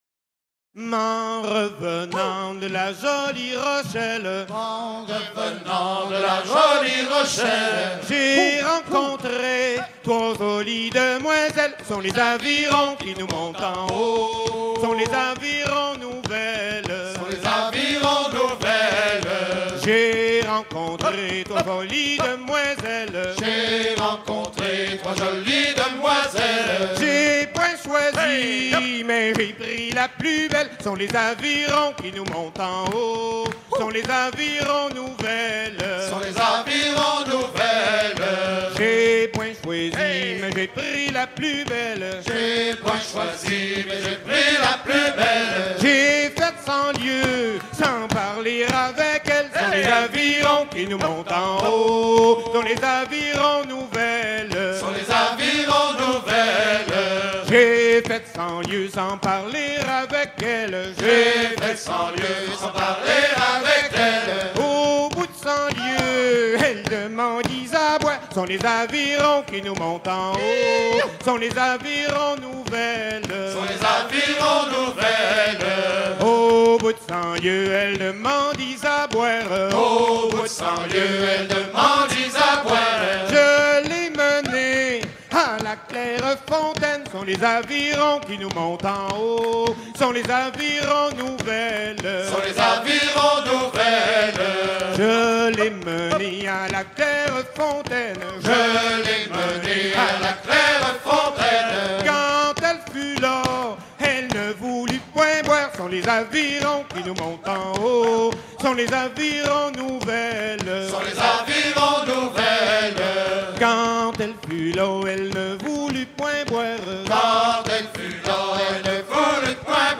Genre laisse
Chants de marins en fête - Paimpol 1999